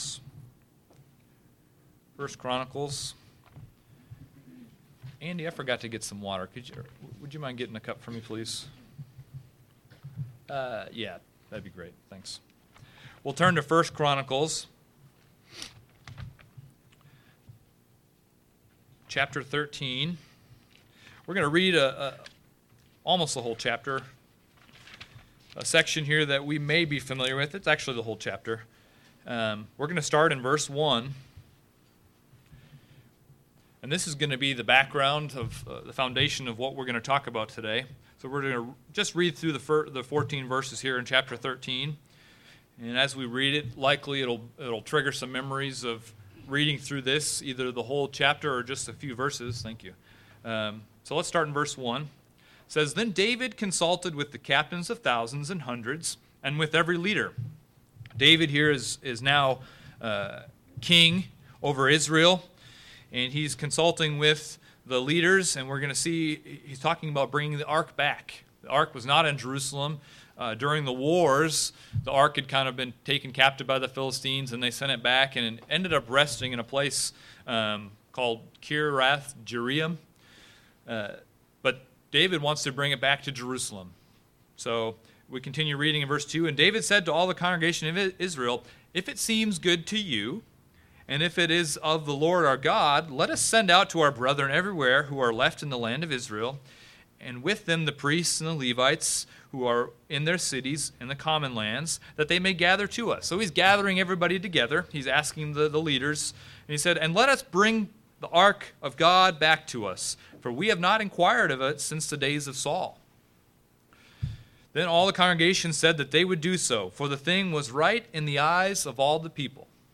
When Uzza took it upon himself to touch the ark of the covenant in 1 Chronicles 13, God took his life. In this sermon, we will explore five lessons we can learn from the death of Uzza.